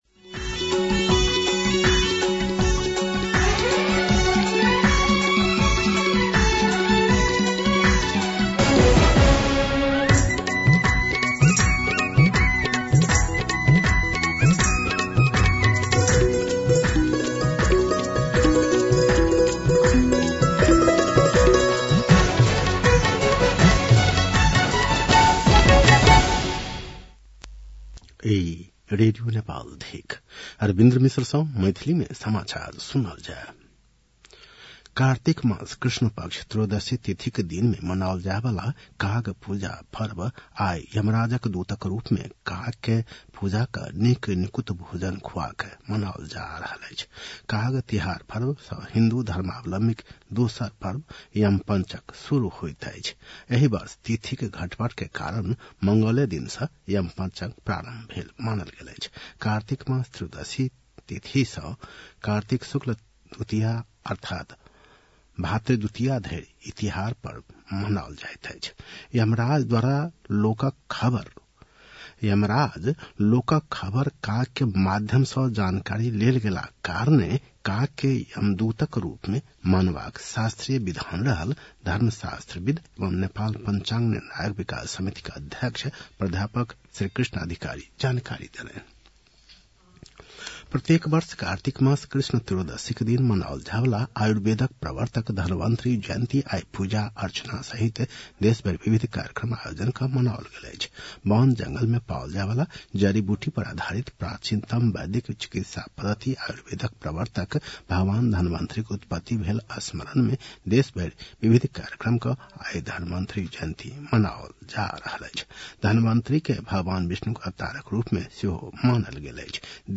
मैथिली भाषामा समाचार : १५ कार्तिक , २०८१